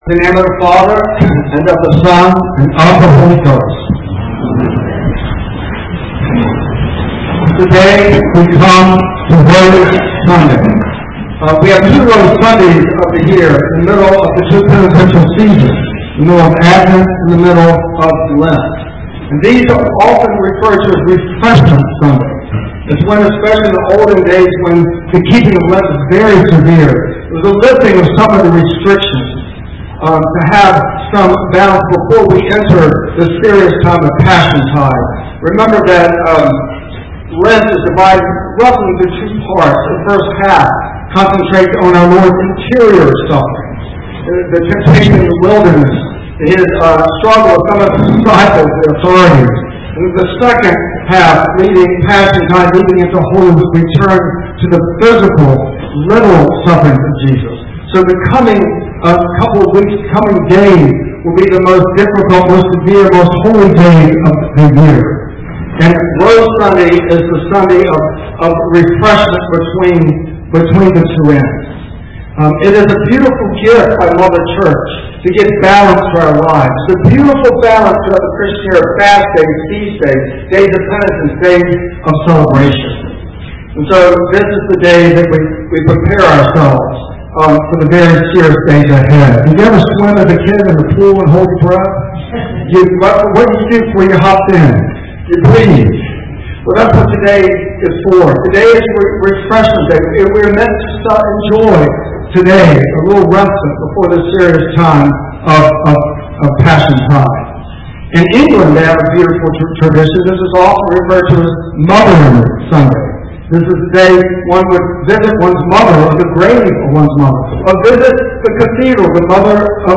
Audio Sermons Never miss the message.